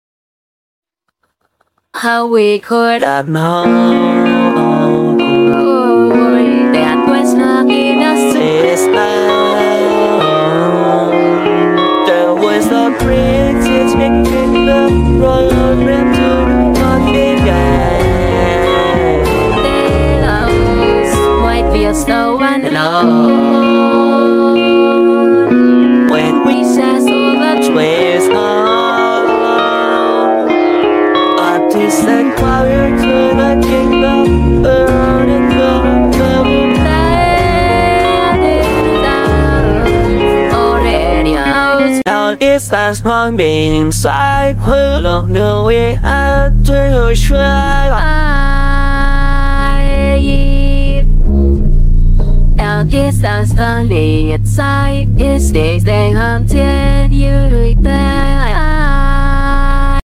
Cover IA